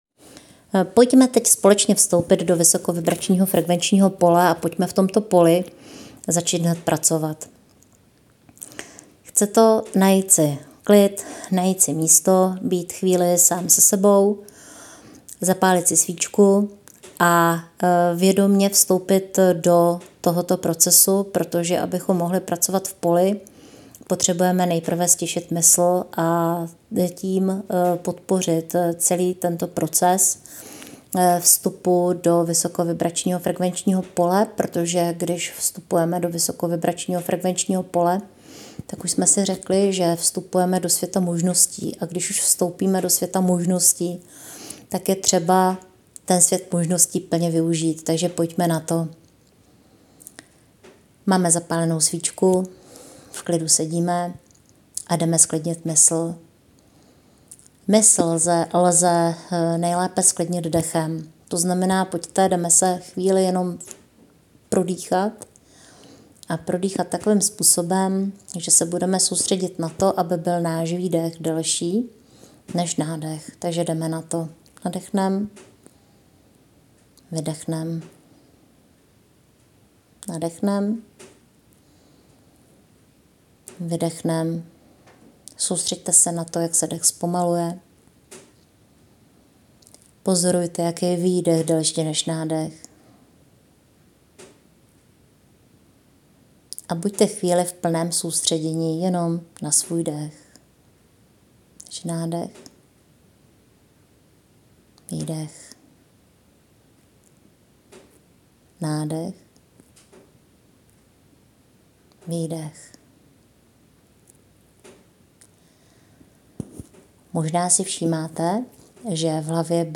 7. 10. MEDITACE - PRÁCE V POLI.m4a